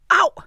traf_ouch3.wav